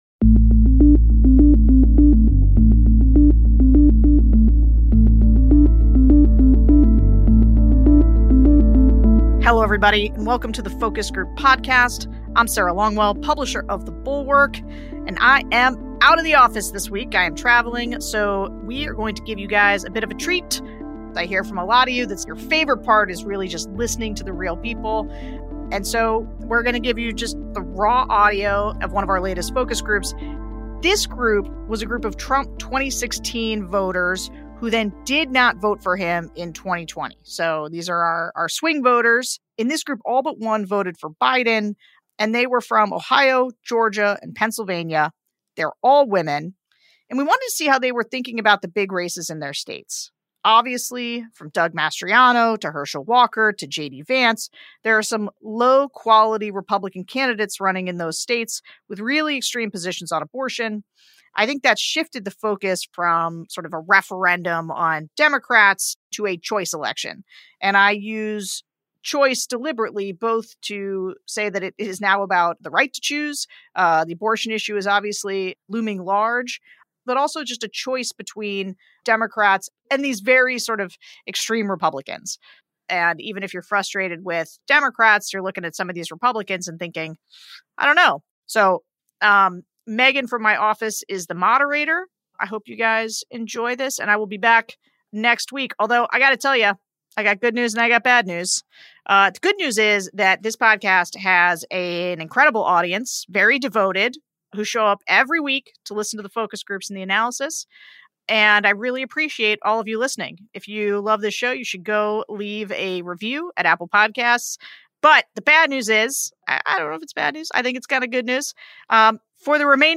On this week's episode, we're sharing a director's cut of a recent focus group, featuring swing state suburban women.